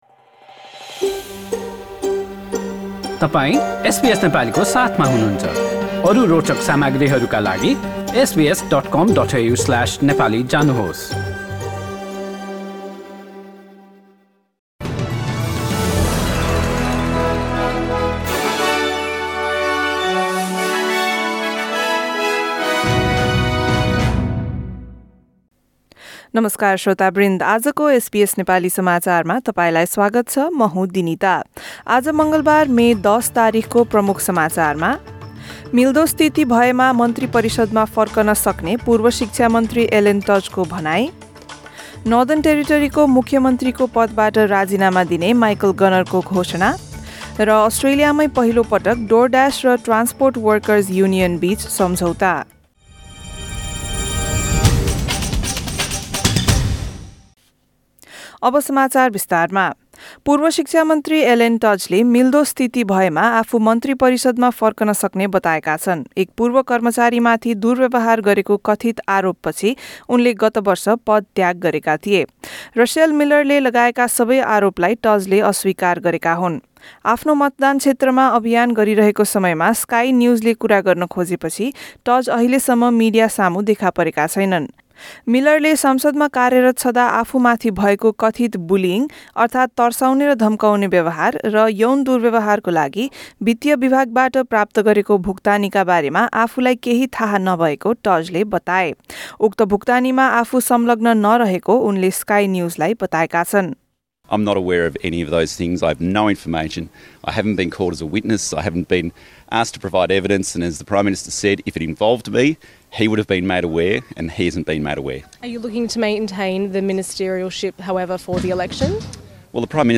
एसबीएस नेपाली अस्ट्रेलिया समाचार: मङ्गलबार १० मे २०२२